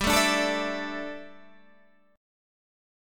Dsus2/G chord